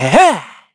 Evan-Vox_Attack3_kr.wav